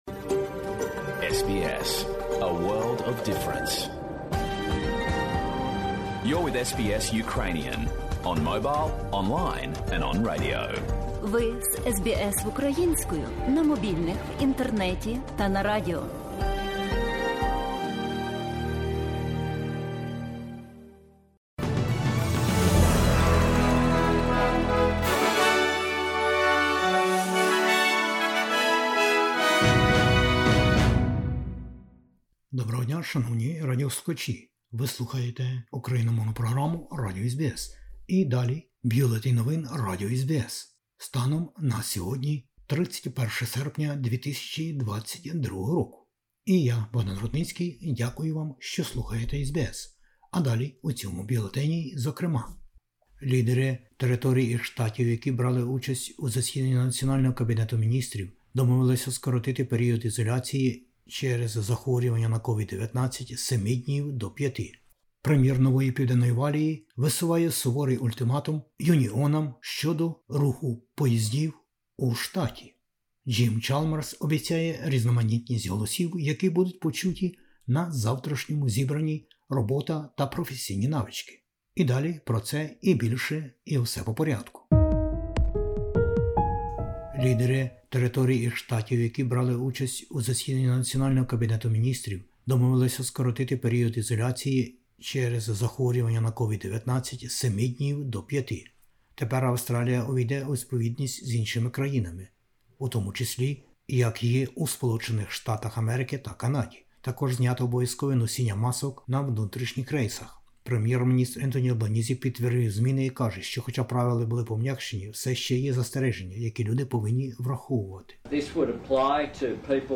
Бюлетень SBS новин - 31/08/2022